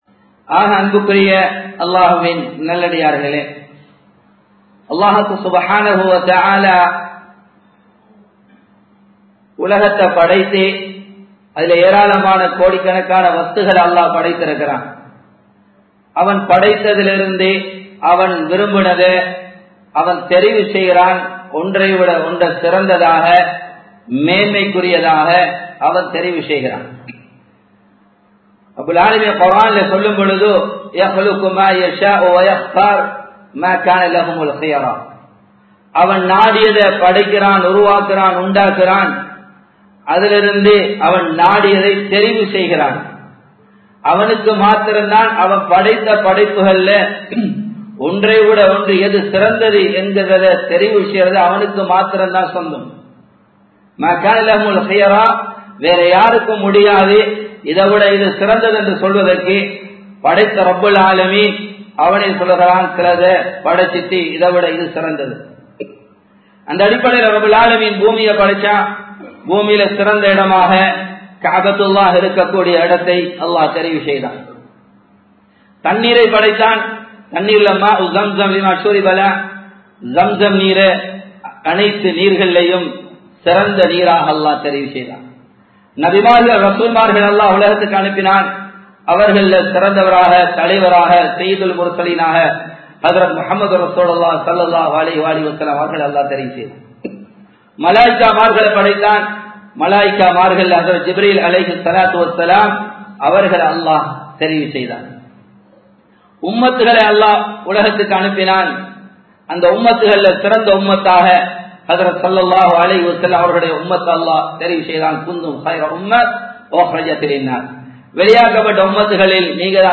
முஹர்ரமும் துன்யாவும் | Audio Bayans | All Ceylon Muslim Youth Community | Addalaichenai
Samman Kottu Jumua Masjith (Red Masjith)